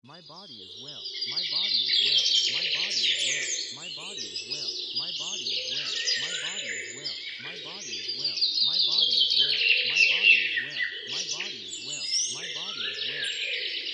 Subliminal messages example:
body-well-edited-subliminal.mp3